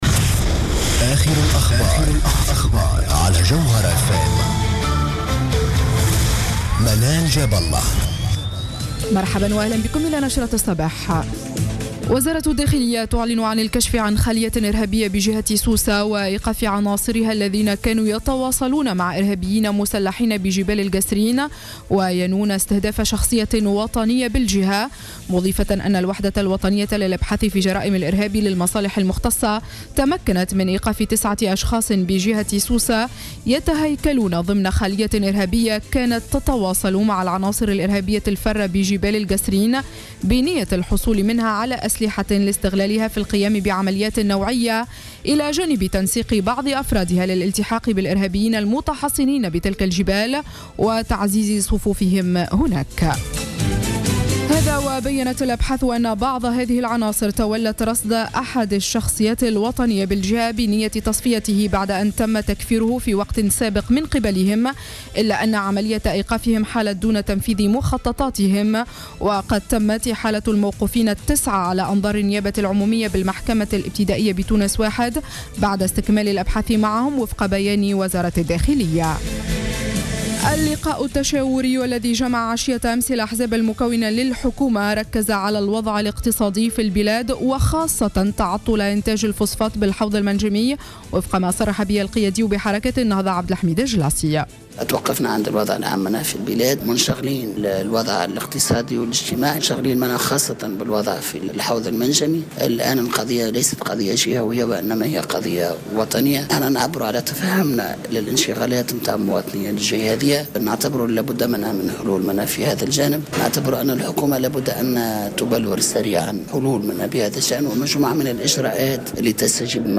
نشرة أخبار السابعة صباحا ليوم السبت 9 ماي 2015